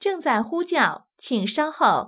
ivr-hold_connect_call.wav